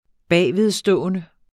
Udtale [ ˈbæˀjveðˌsdɔˀənə ]